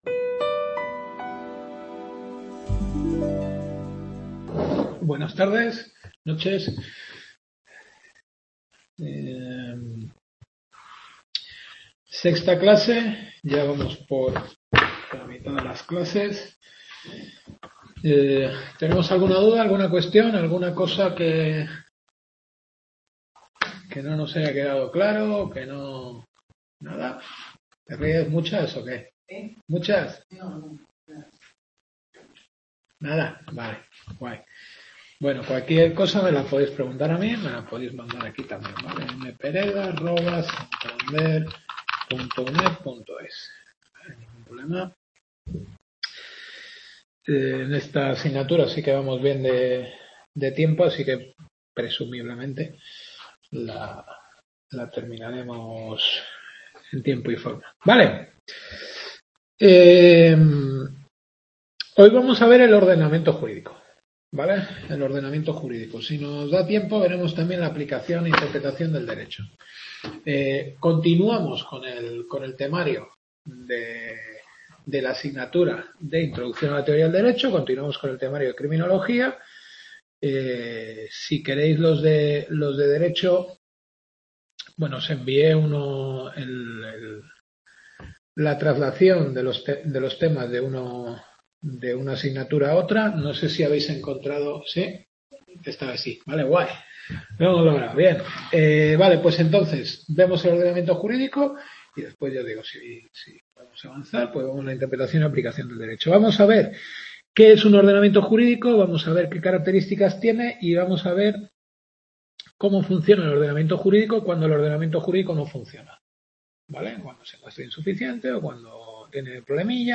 Introducción a la Teoría del Derecho-Teoría del Derecho. Sexta Clase.